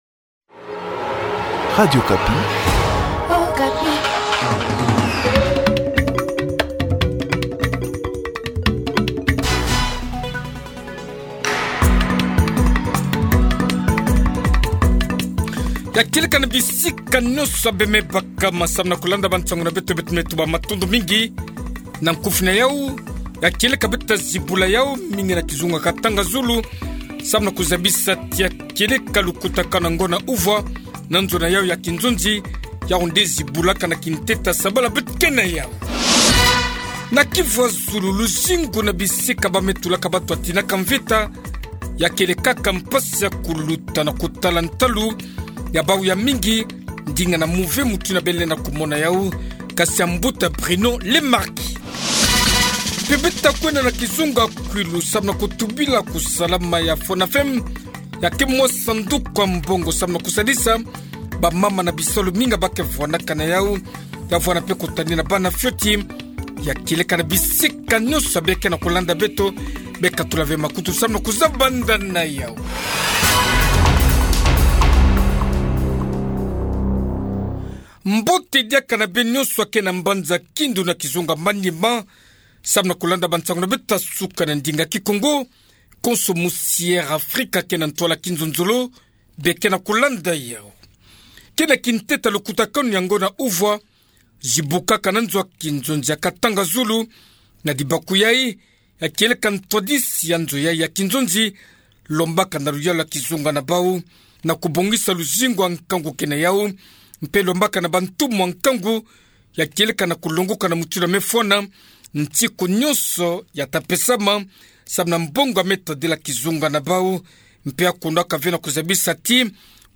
Na Kivu ya zulu, luzingu na bisika ya ba me tulaka bantu ya tinaka mvita me kuma ya mpasi kuluta, na kutala ntalu na bau ya mingi. Ndinga ya mbuta Bruno Lemarquis. Mpe, beto ta kwenda na kizunga ya Kwilu, samu na kutubila kusalama ya Fonafen, mwa sanduku ya mbongo samu na kusadisa bamama mpe kutanina bana ya fioti.